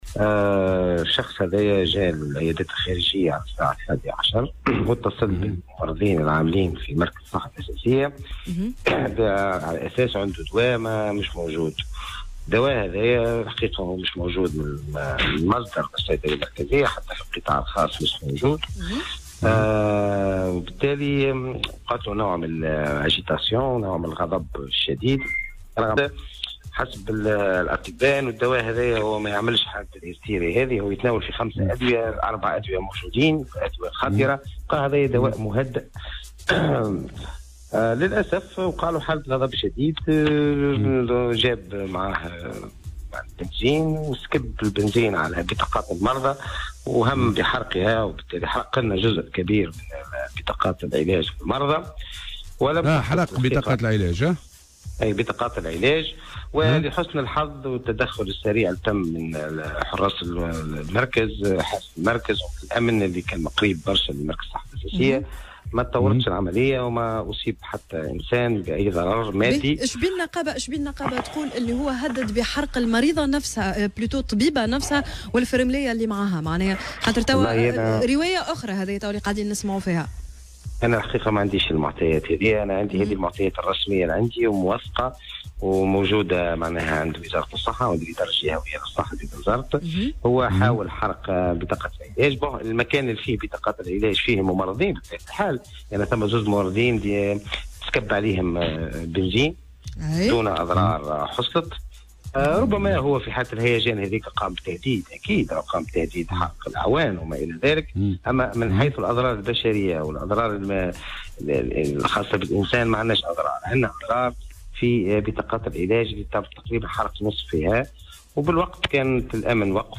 في مداخلة له اليوم في برنامج "صباح الورد" على "الجوهرة أف أم"